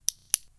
Add click click with ap disc / at disc
autopilot-disconnect-button.wav